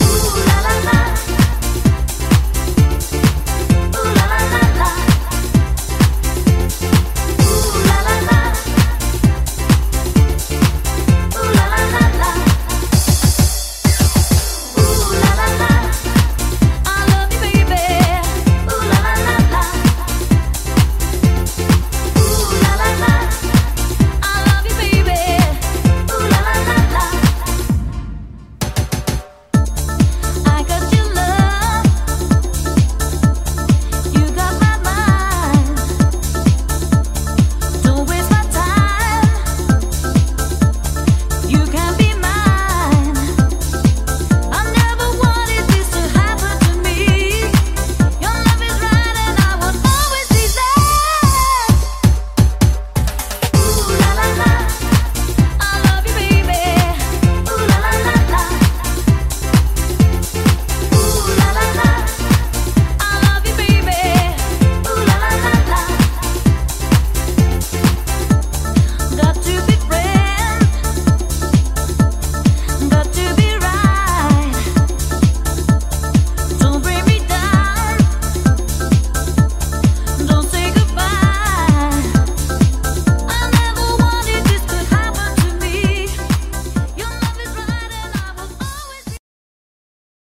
BPM130--1
Audio QualityMusic Cut